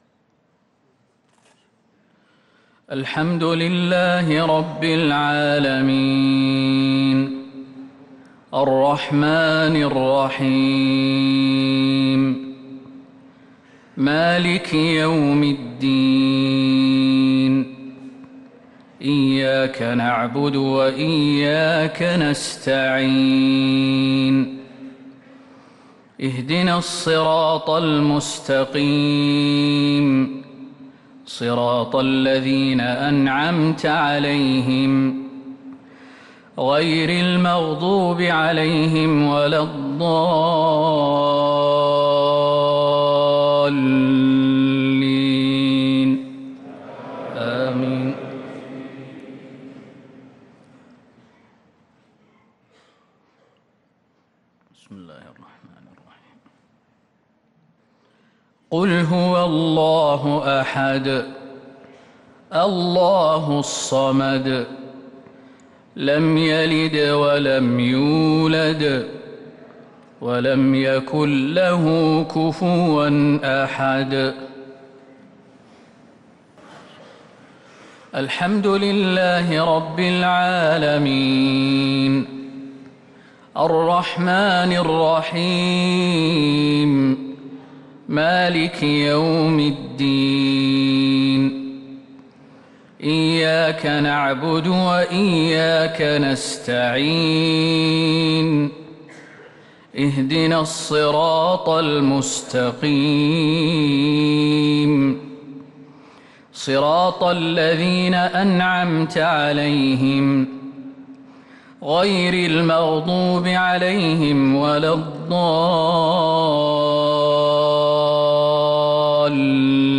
مغرب الاثنين 3 محرم 1444هـ سورتي الإخلاص و الفلق | Maghreb prayer Surah Al-Ikhlas and Al-Falaq 1-8-2022 > 1444 🕌 > الفروض - تلاوات الحرمين